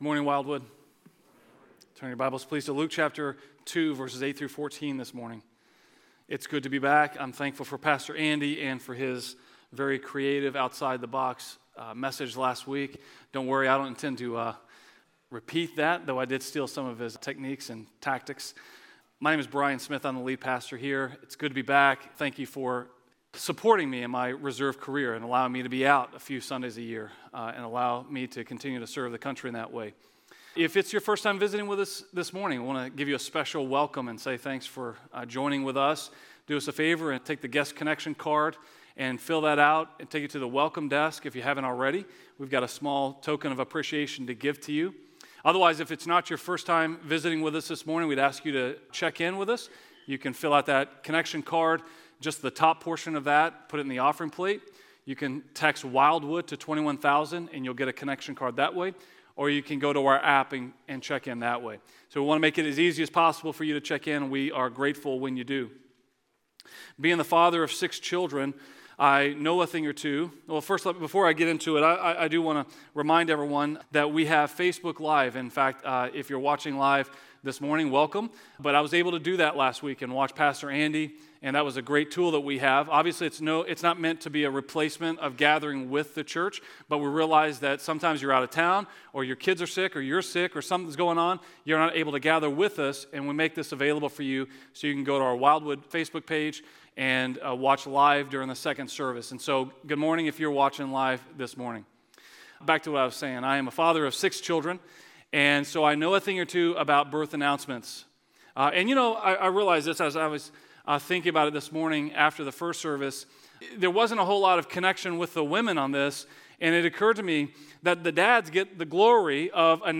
So many names in this sermon!